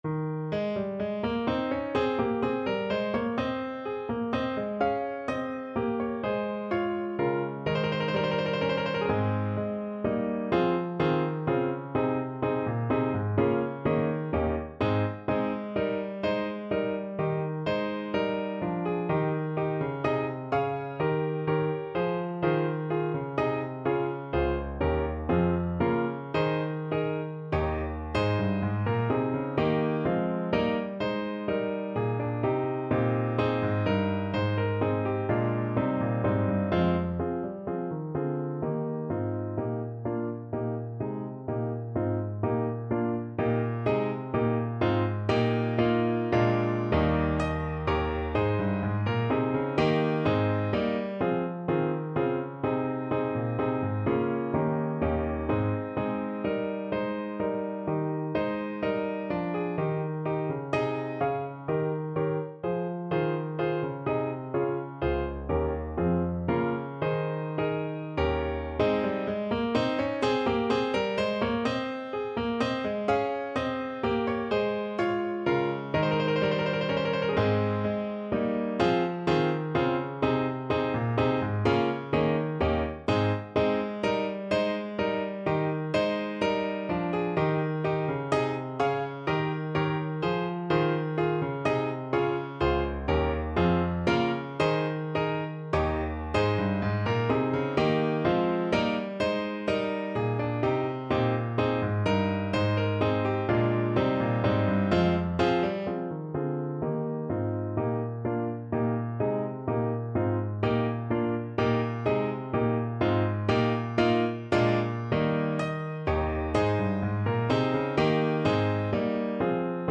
3/4 (View more 3/4 Music)
Allegro giusto =126 (View more music marked Allegro)